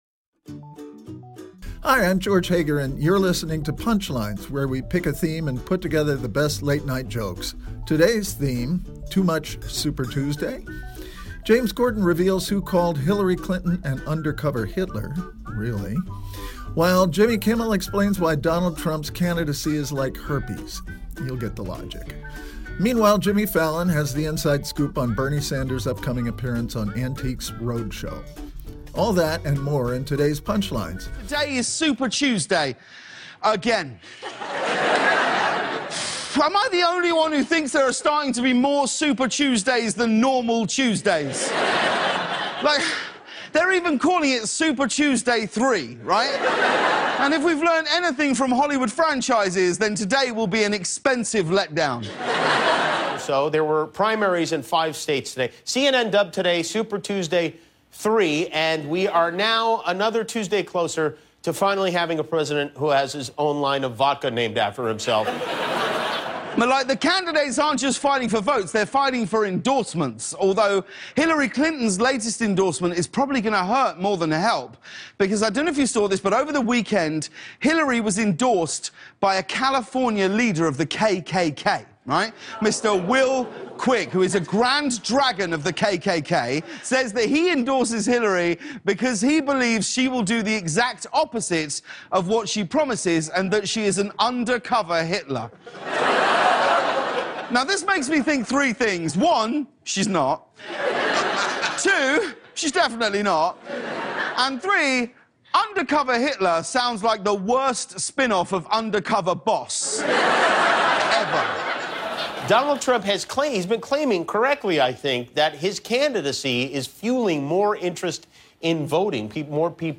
The late-night comics on the third Super Tuesday in the 2016 primary.